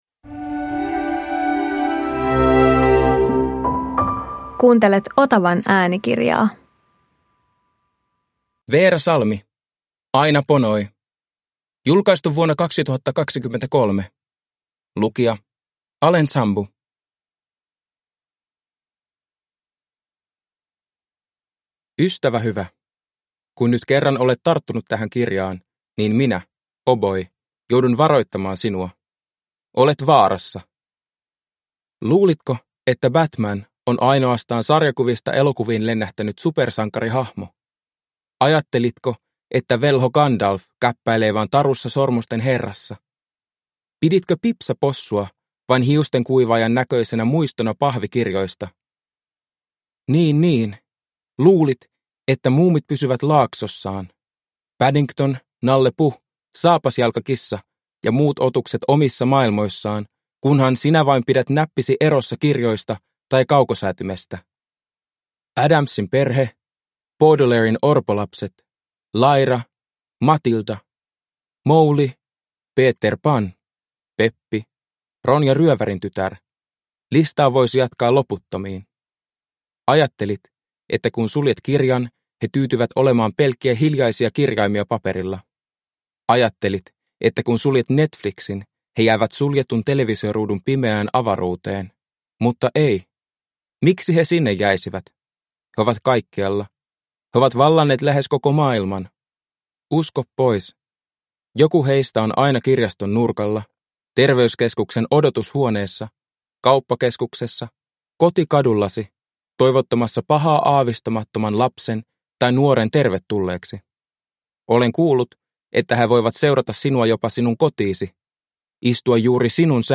Aina Ponoi – Ljudbok – Laddas ner